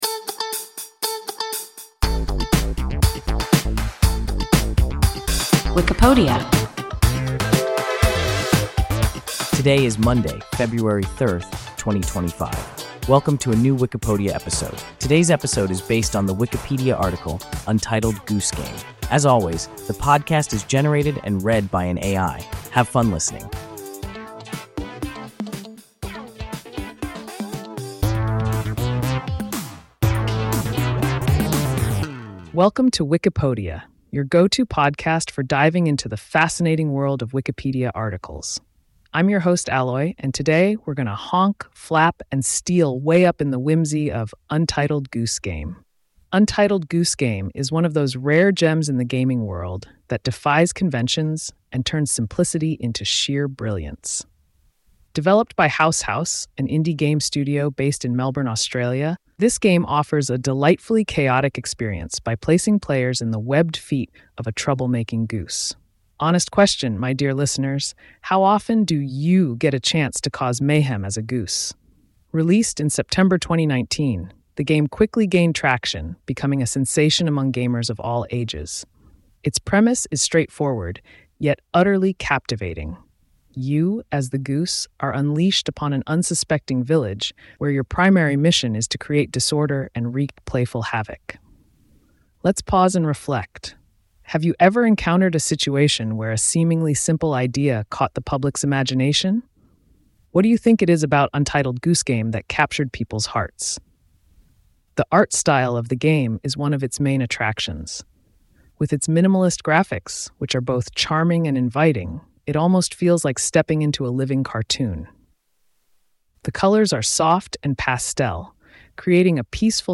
Untitled Goose Game – WIKIPODIA – ein KI Podcast